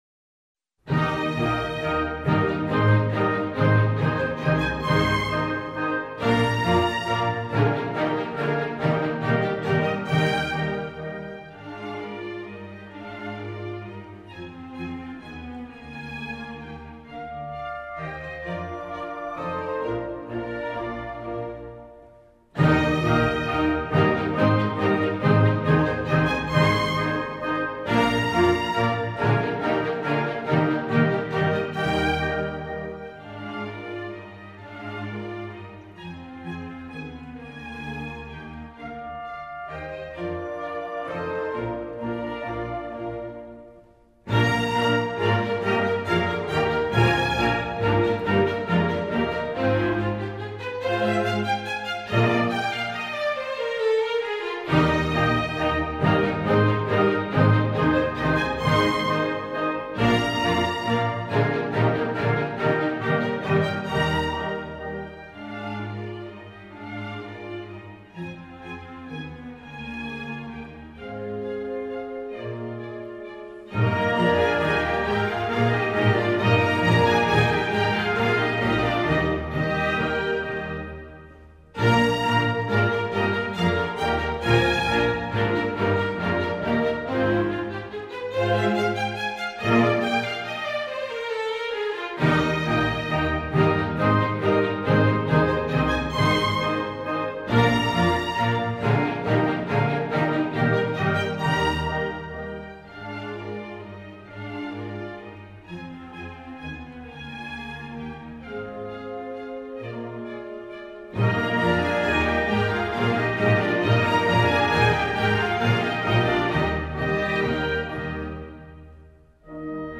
谢谢楼主....轻快极适合小朋友